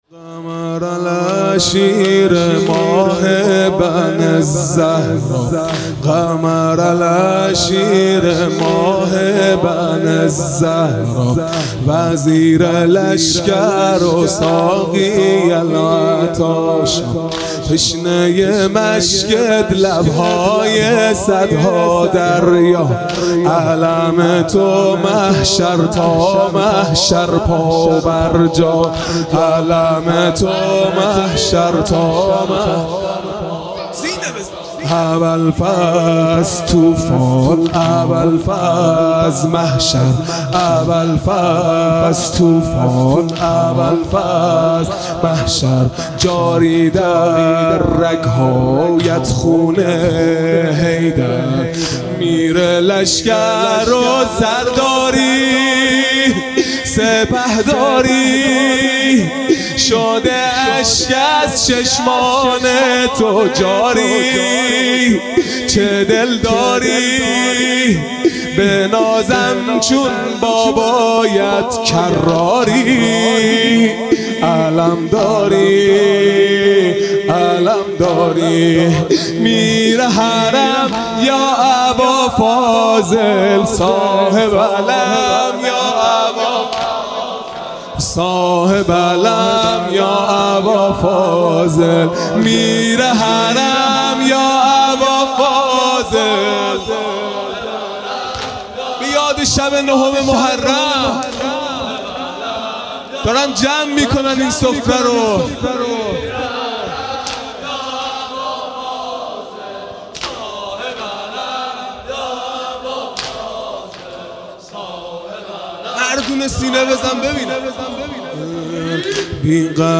خیمه گاه - هیئت بچه های فاطمه (س) - واحد | قمر العشیره، ماه بن الزهرا